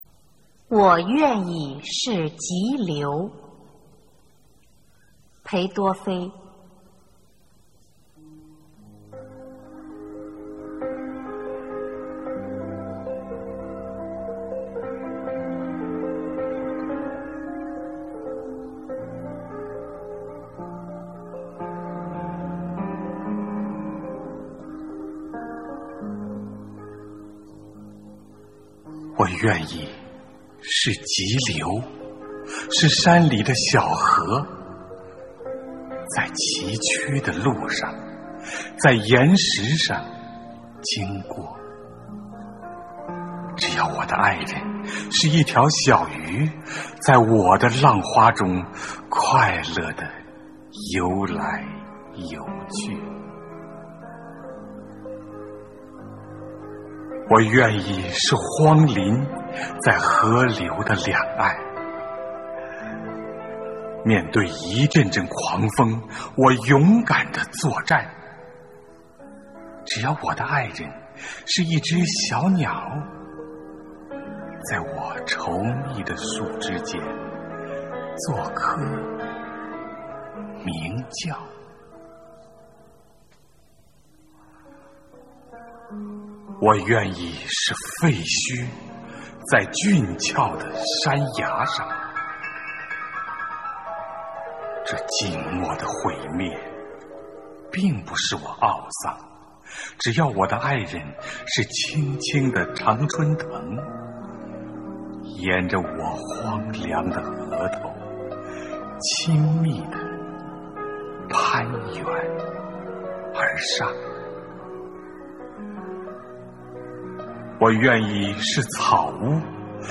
《我愿意是急流》朗诵